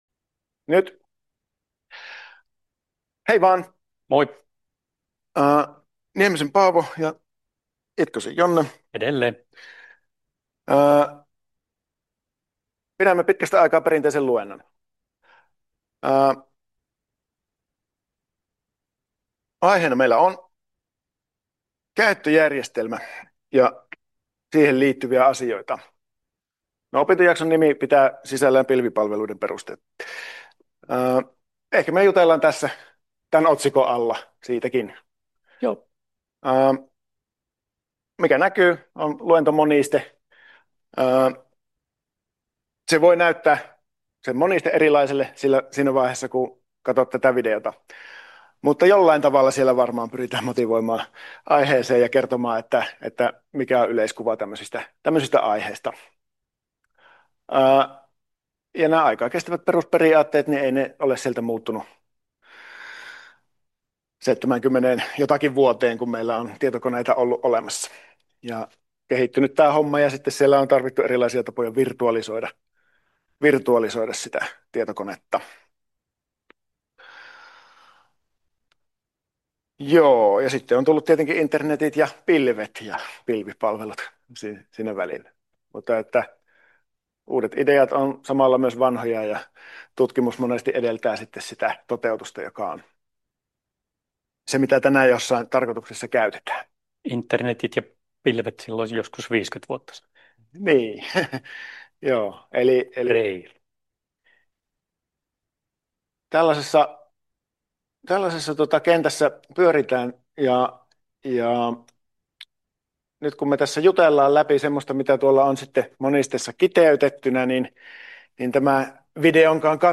"Perinteinen luento" (2026 versio). Tarkoittaa muun muassa opettajavetoista selostusta monistetekstin äärellä.